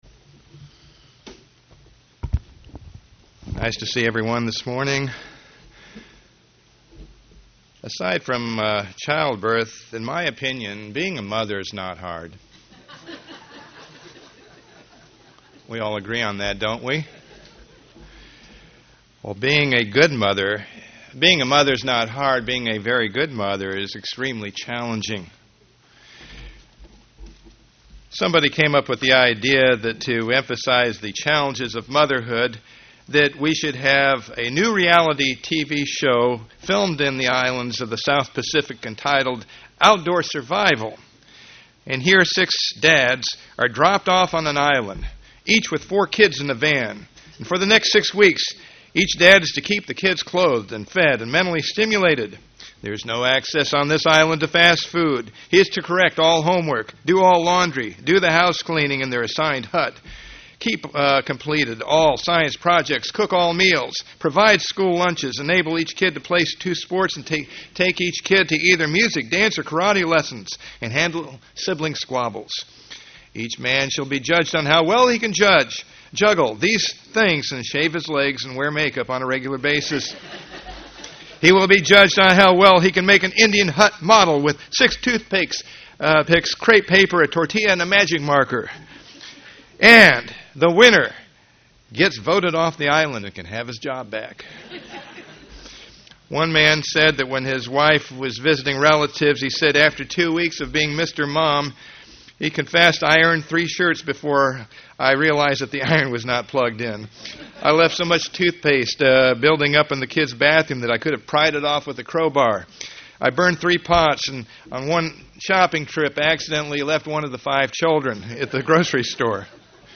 Given in St. Petersburg, FL
We also look into the lives of some of the biblical mothers and their influence on the prophets born to them; e.g. – Samuel, Jesus UCG Sermon Studying the bible?